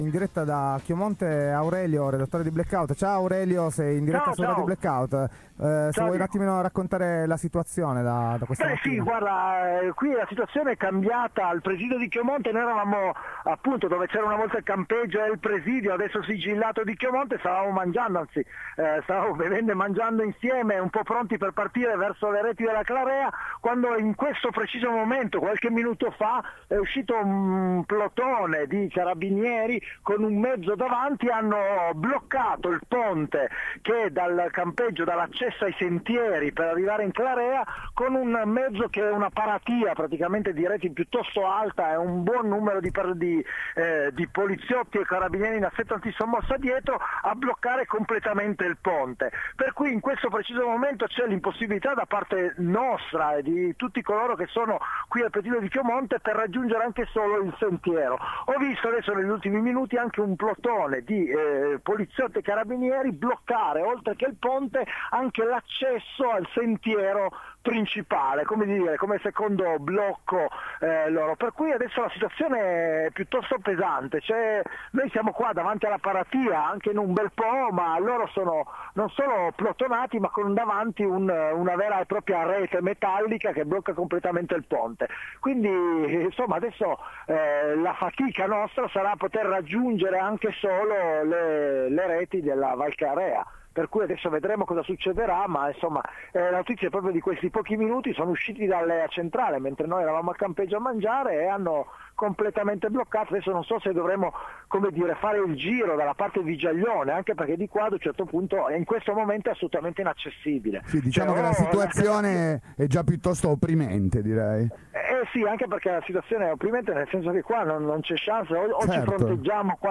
–Dirette–